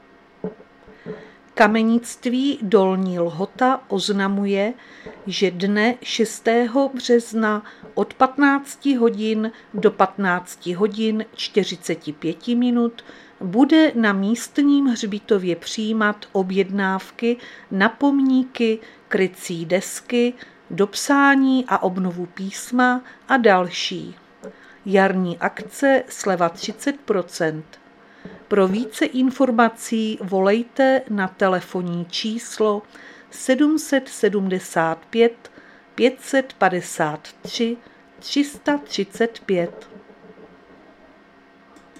Záznam hlášení místního rozhlasu 2.3.2026
Zařazení: Rozhlas